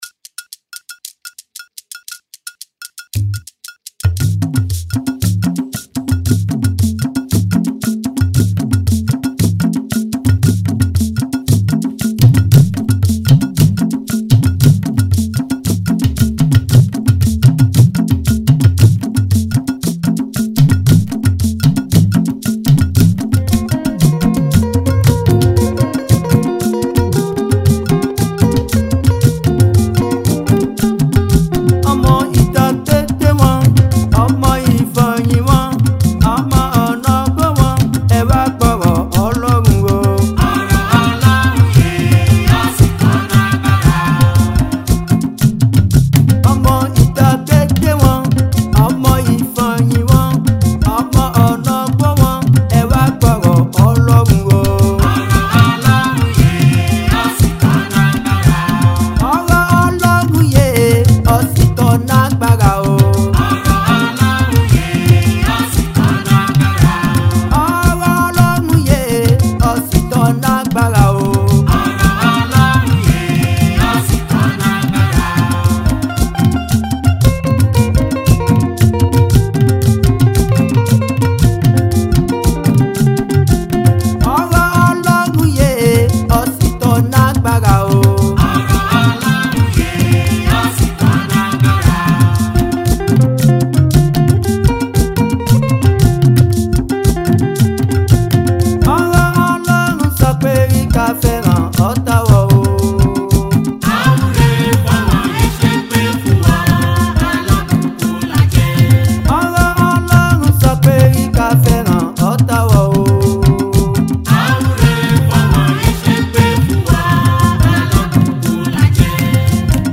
Chant bolojo : Ɔ̀RƆ̀ ƆLƆHUN YÈ